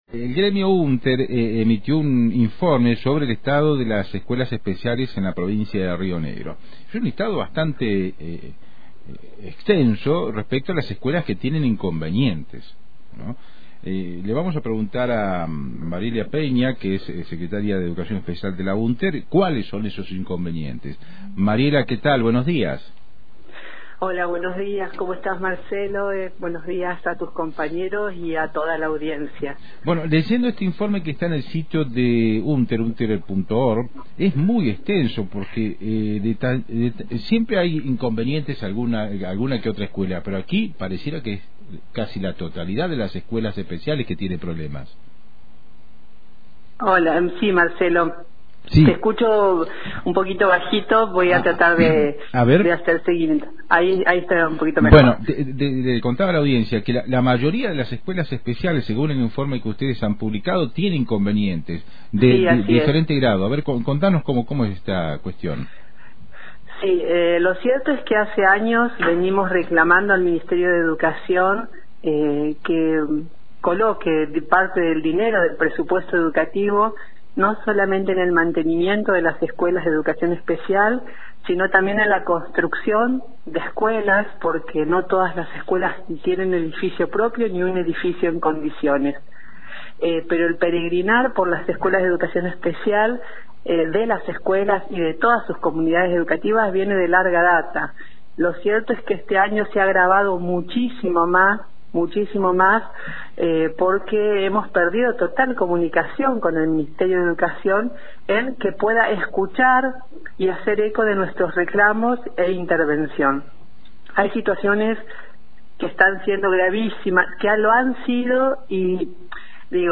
En diálogo con Antena Libre